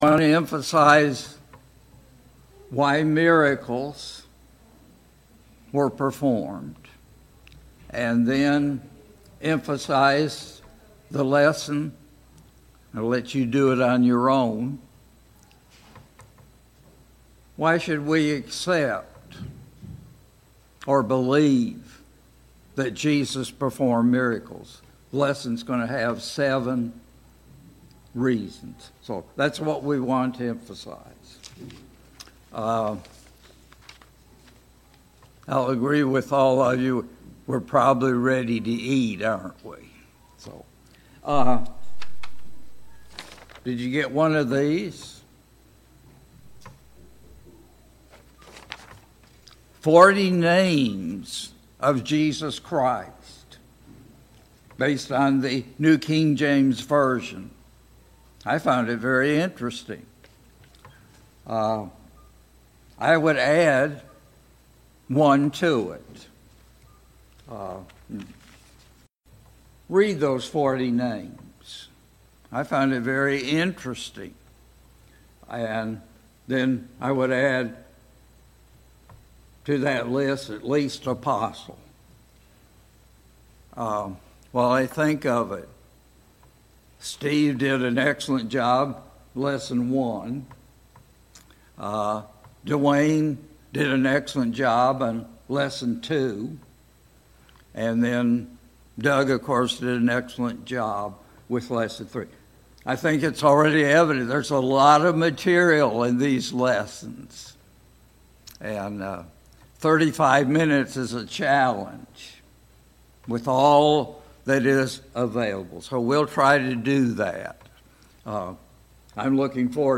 4. Jesus, the Almighty – 2025 VBS Adult Lesson